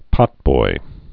(pŏtboi)